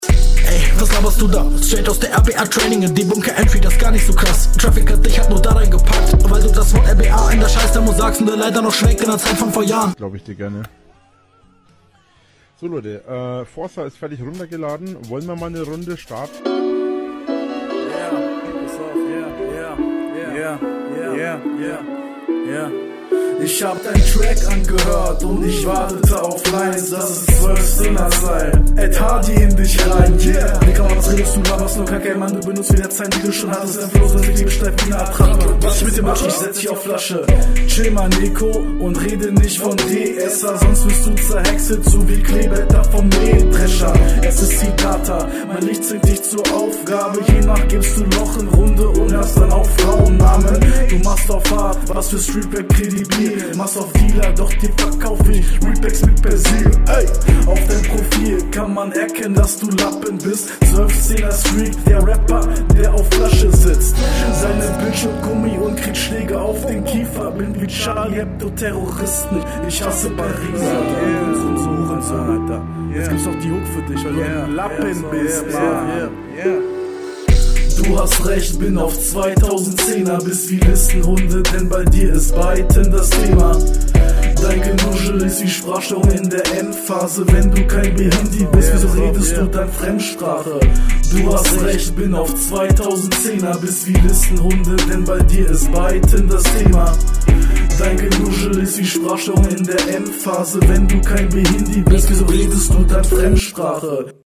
Forza Anspielung wegen Beat sehr gut mir sind die Doubles zu viel.
Flow ist gut, aber verhaspler